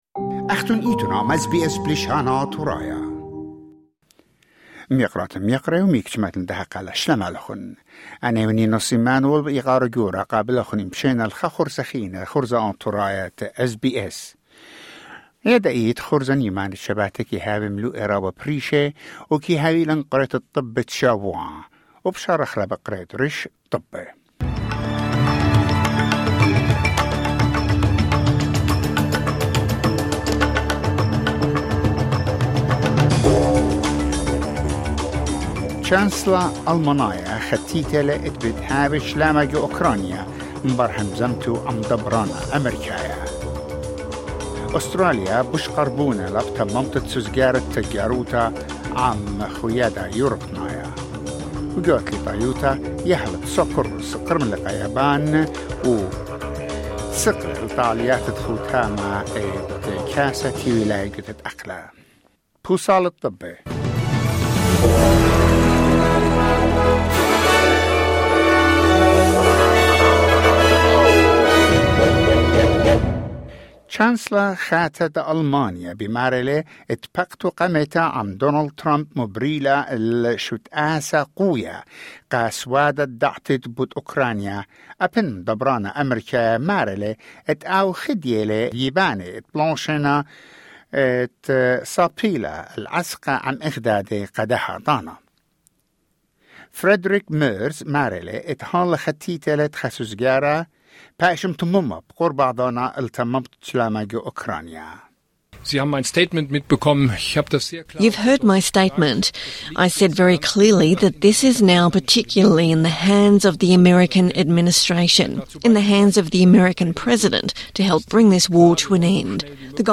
SBS Assyrian: Weekly news wrap